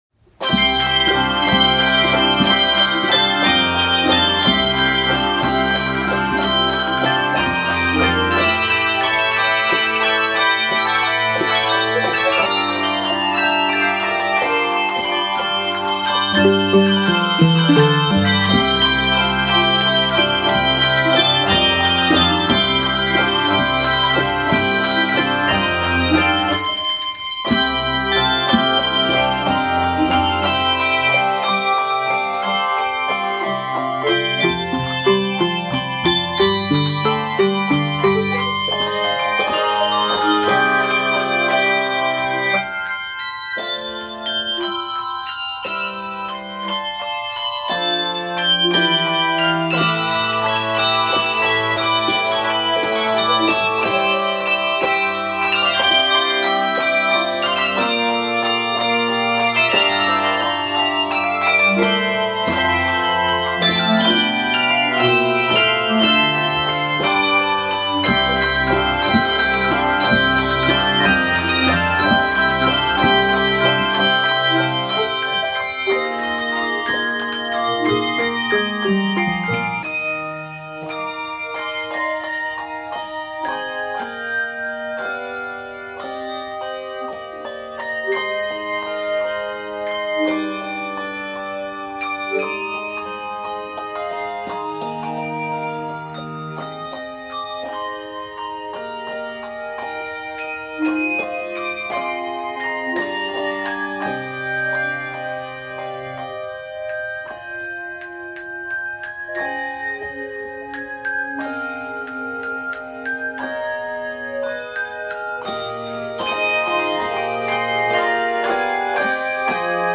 Keys of C Major and Eb Major.